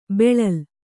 ♪ beḷal